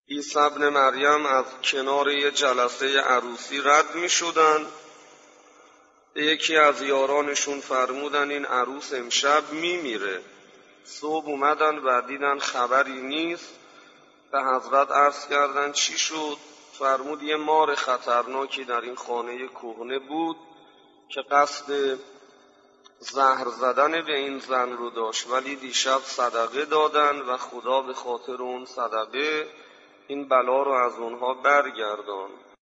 شهادت استاد انصاریان سخنرانی